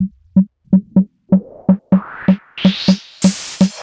Index of /90_sSampleCDs/Classic_Chicago_House/FX Loops